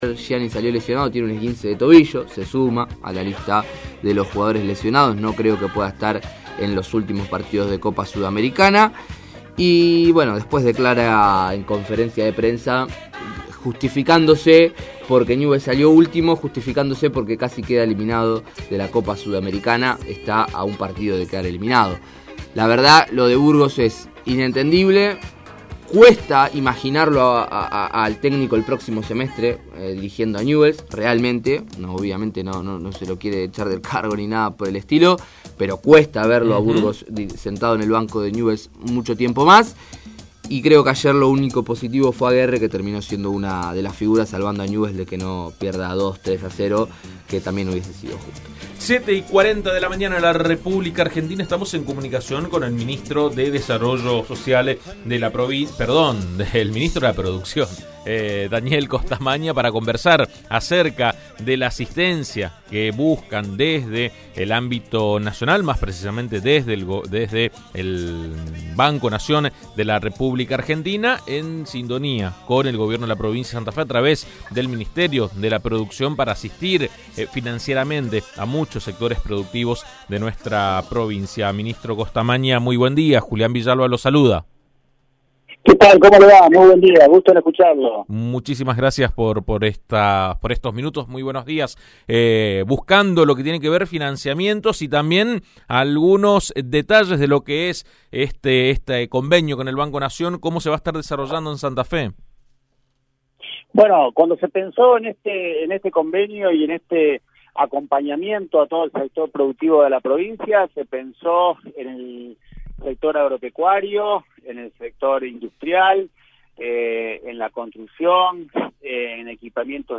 El ministro de Producción, Ciencia y Tecnología de la provincia, Daniel Costamagna, habló con AM 1330 acerca de los avances en la implementación del crédito de 26.500 millones de pesos otorgado por el Banco Nación para impulsar a empresas y productores santafesinos.